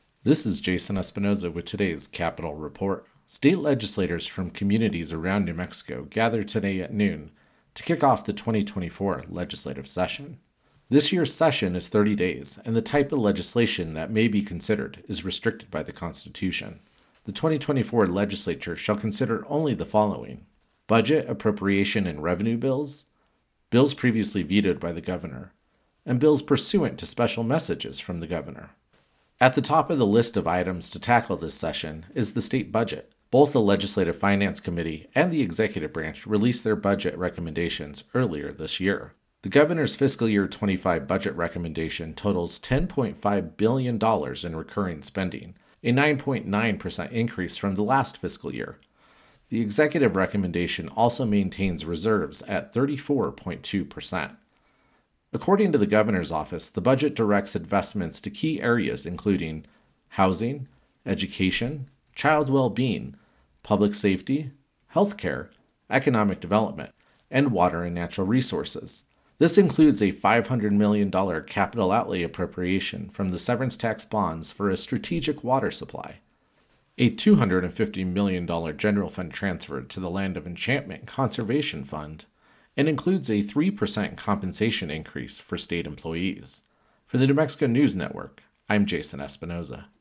capitol reports